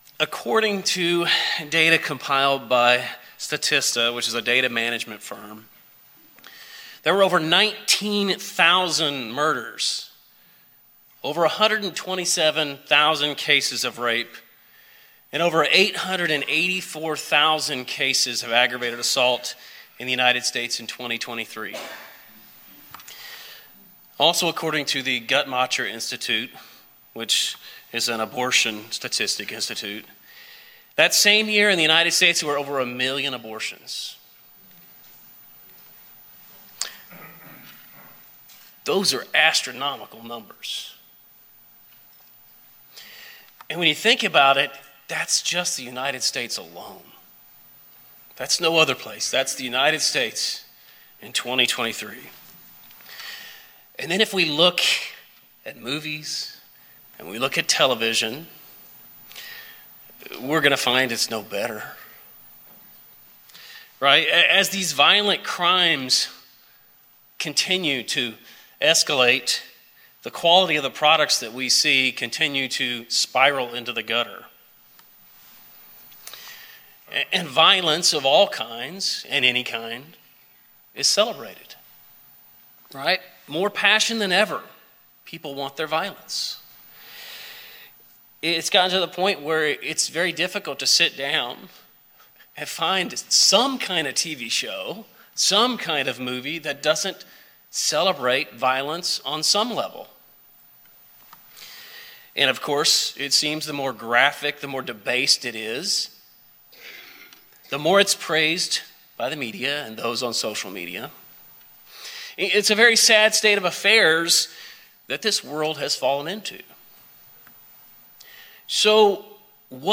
In this split sermon, we'll see what value God places on human life and how it should affect how we deal with some difficulties we face.